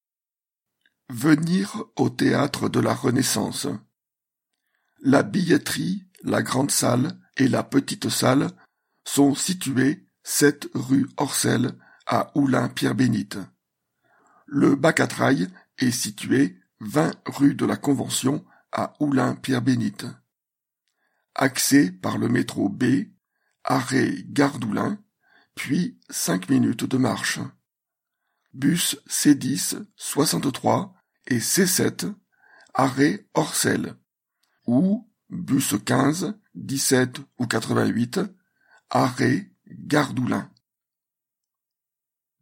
Présentation audio pour les personnes déficientes visuelles